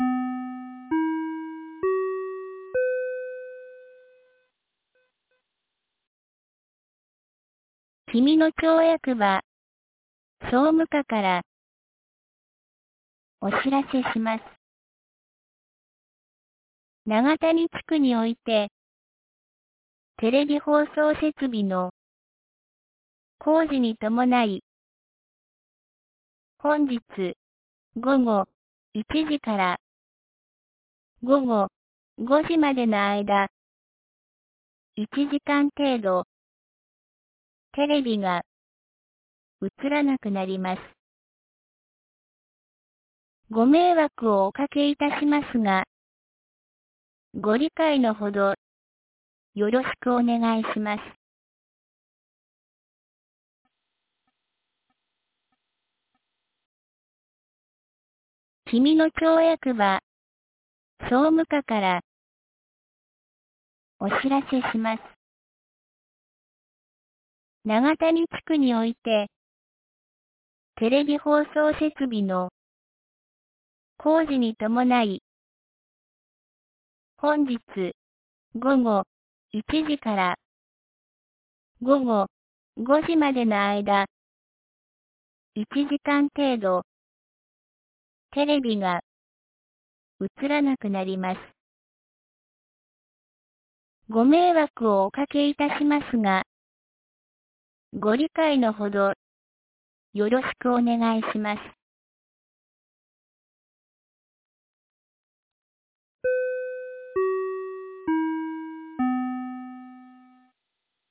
2024年01月18日 10時01分に、紀美野町より東野上地区へ放送がありました。
放送音声